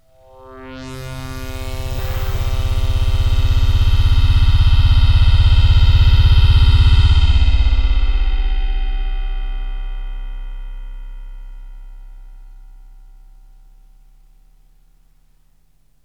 AMBIENT ATMOSPHERES-2 0002.wav